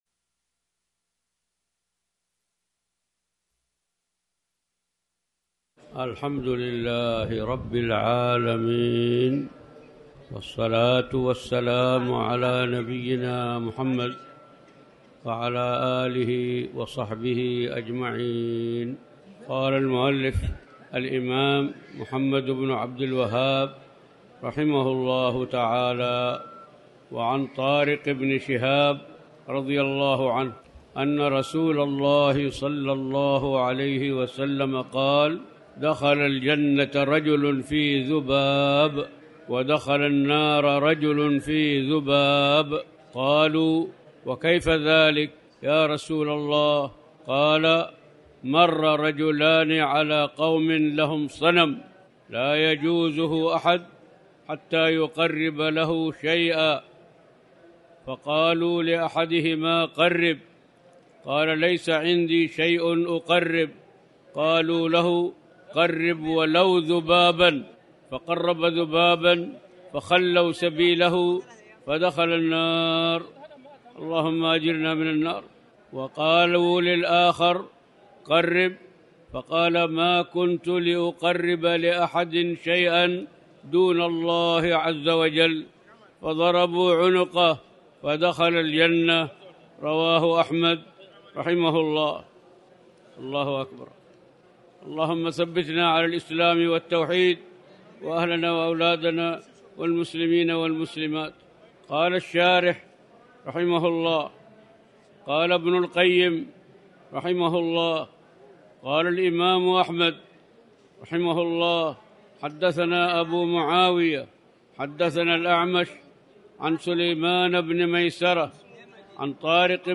تاريخ النشر ١٧ ربيع الأول ١٤٤٠ هـ المكان: المسجد الحرام الشيخ